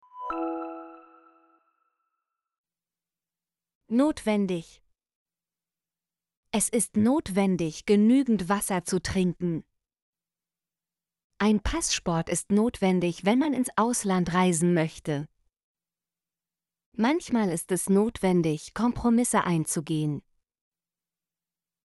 notwendig - Example Sentences & Pronunciation, German Frequency List